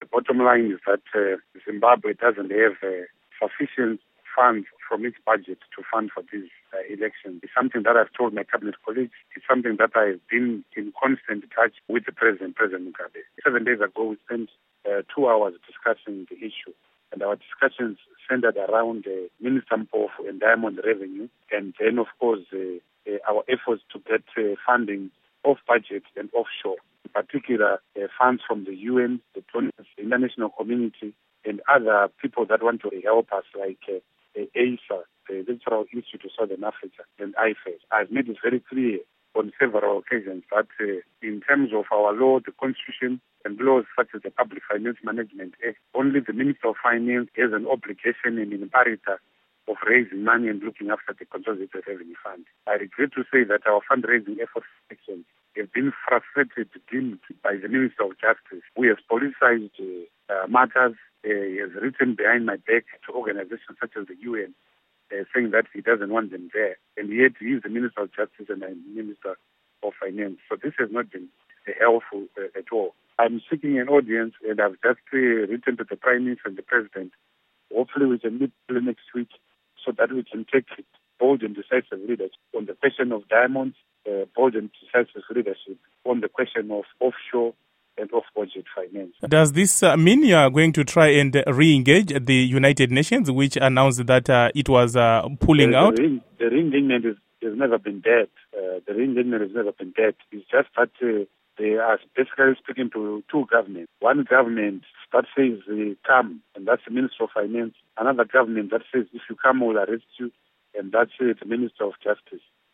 Interview With Tendai Biti